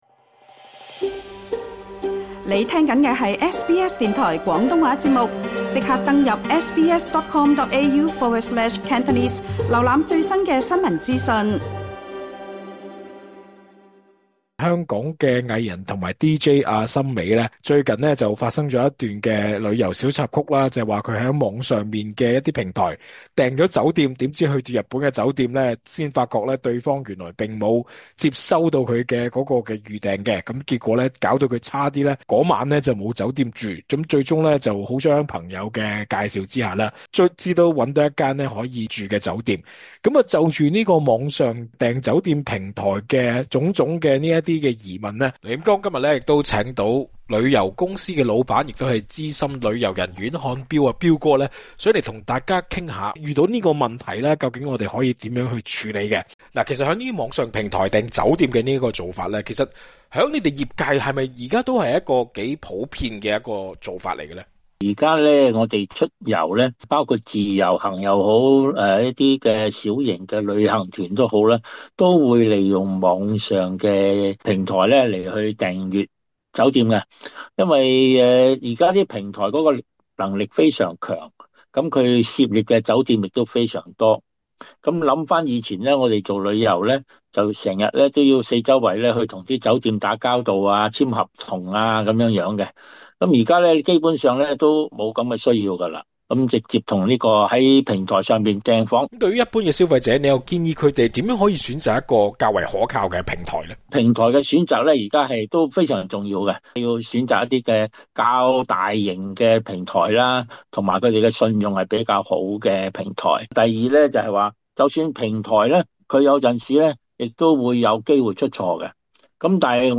詳盡訪問： LISTEN TO 【外遊唔想「7街瞓」】澳洲人遇訂房平台出錯點解決？